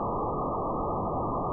engine.ogg